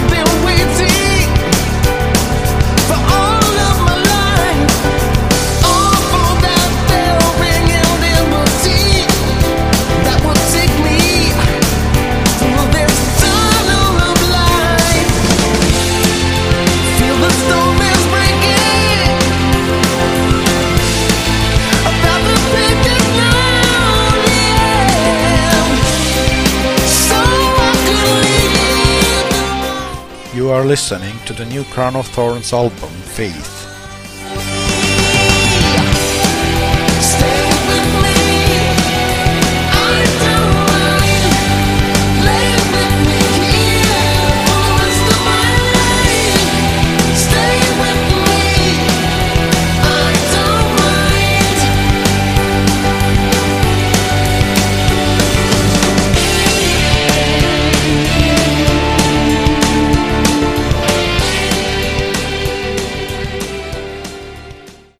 Category: AOR
lead and backing vocals
keyboards
drums, percussion